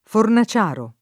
fornaciaio